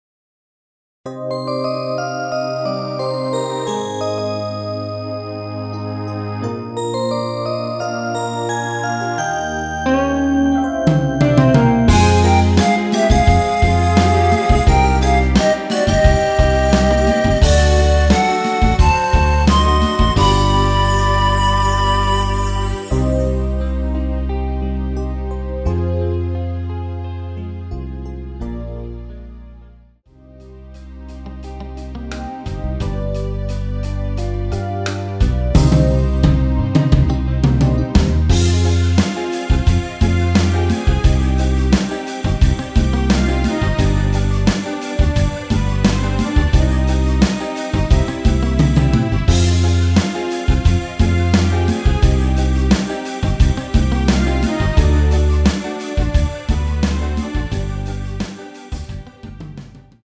MR입니다.
원곡의 엔딩이 길고 페이드 아웃이라 라이브 하시기 좋게 엔딩을 4마디로 편곡 하였습니다.
앞부분30초, 뒷부분30초씩 편집해서 올려 드리고 있습니다.